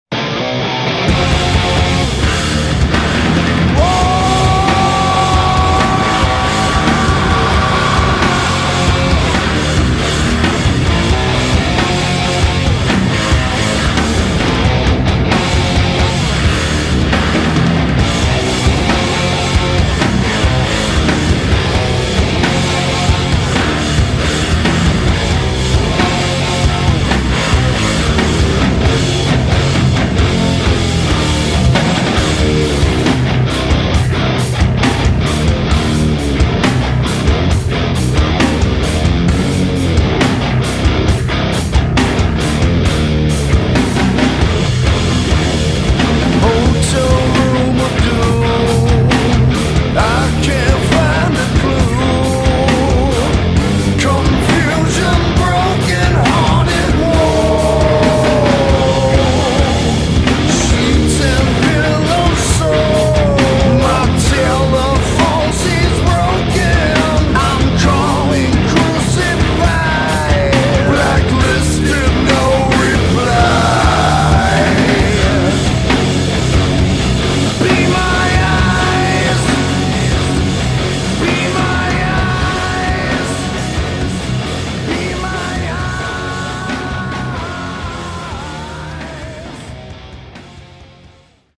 Talmente denso da sembrare una coperta di fango.